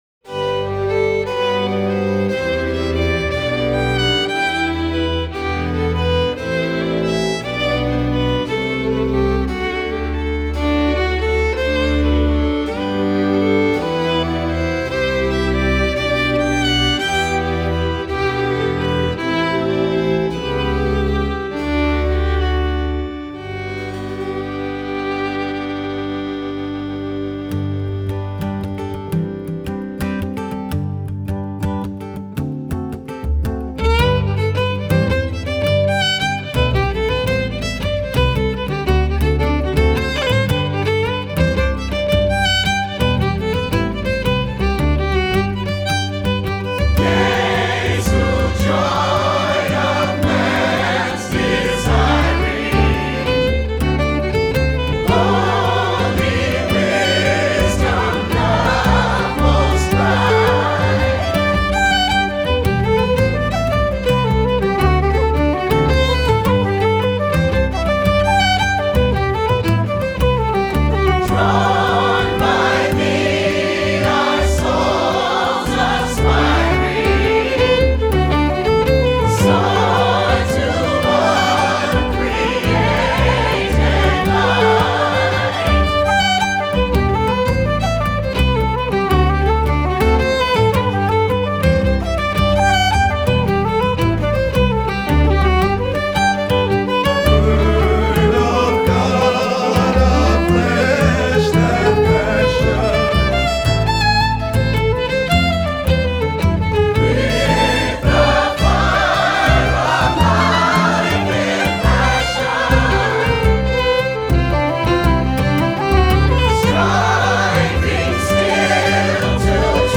virtuoso fiddler